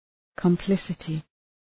Shkrimi fonetik {kəm’plısətı}